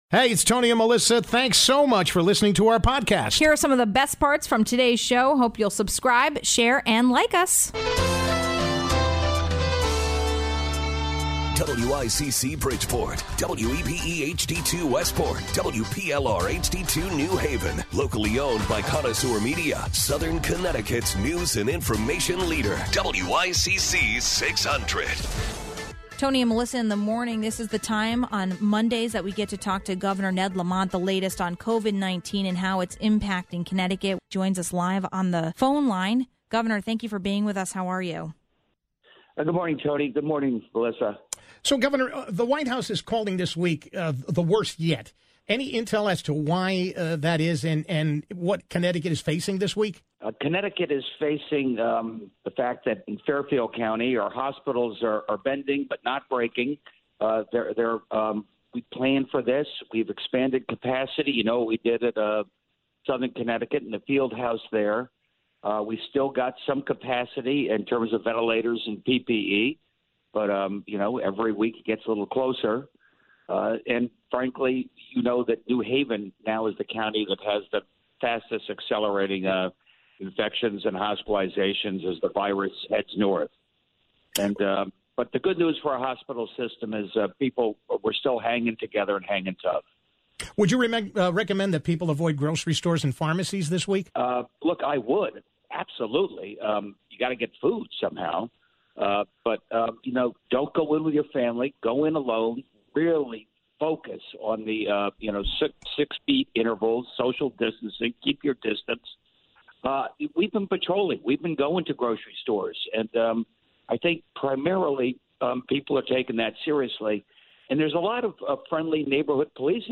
1. Governor Lamont answers your questions on covid-19 ((00:08))